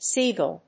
発音記号
• / ˈsigʌl(米国英語)
• / ˈsi:gʌl(英国英語)